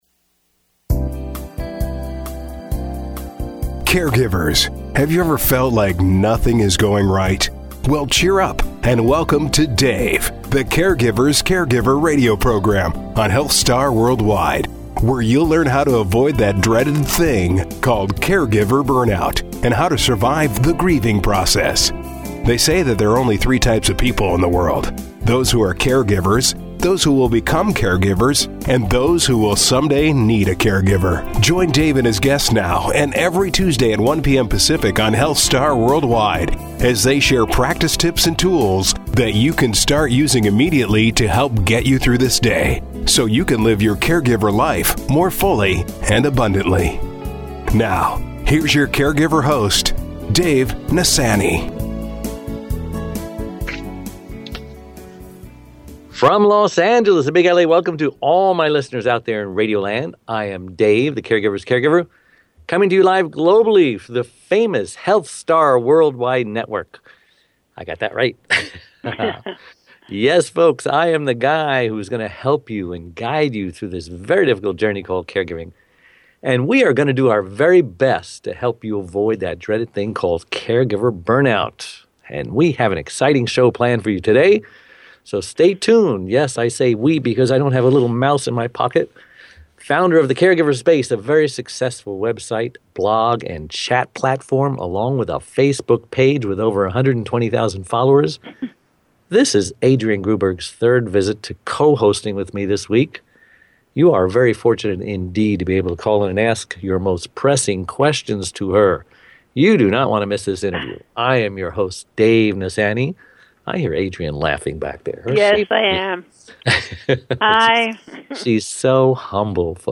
Hear the comments and suggestions from our callers.